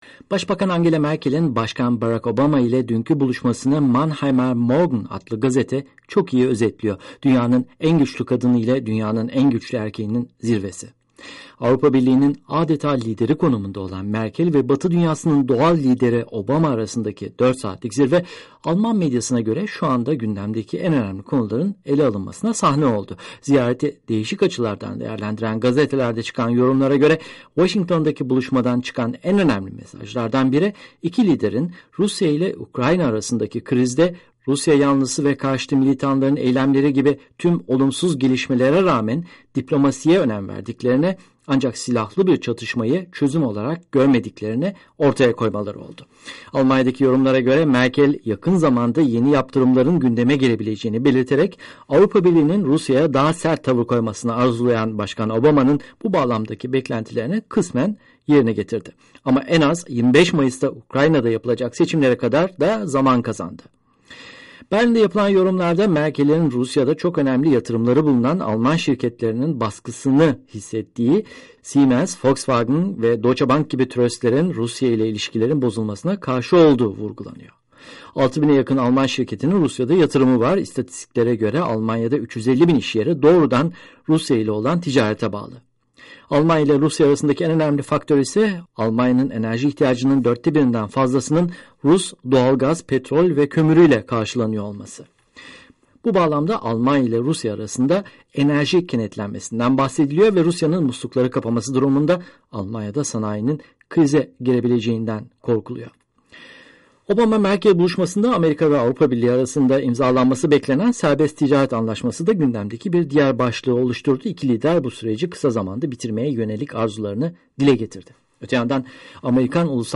haberi